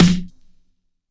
DUFF_SNR.wav